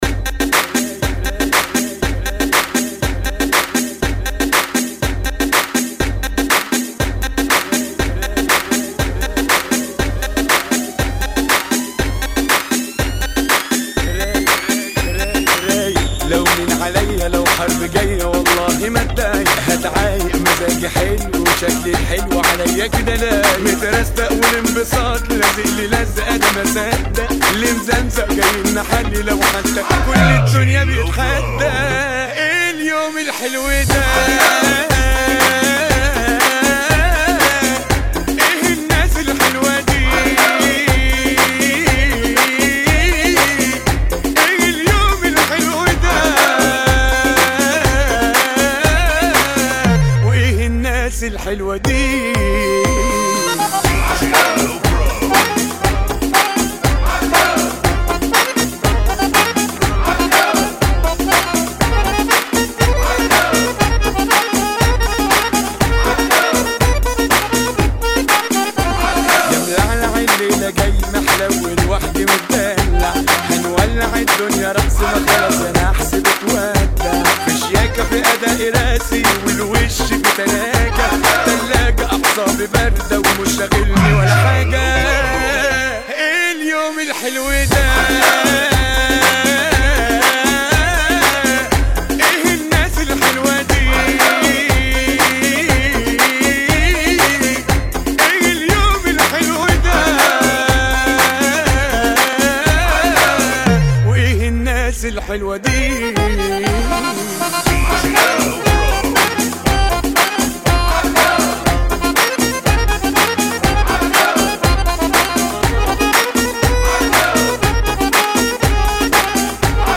[ 120 Bpm ]